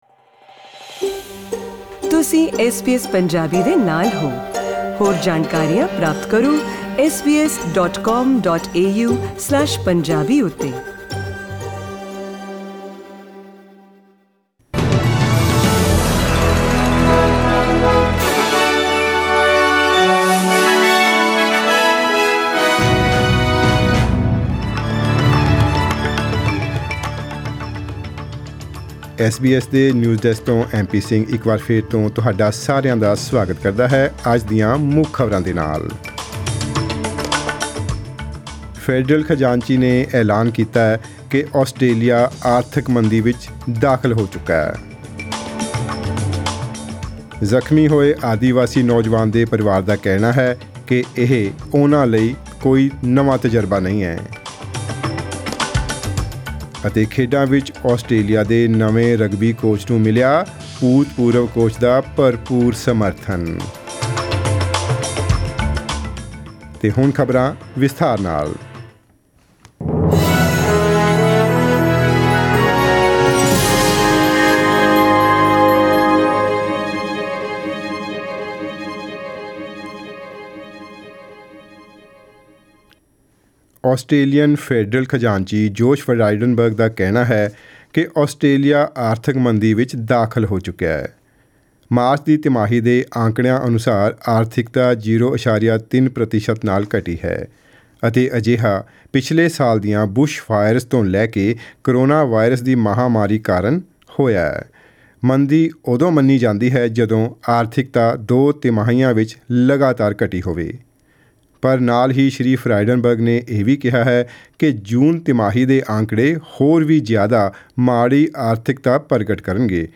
Australian News in Punjabi: 3 June 2020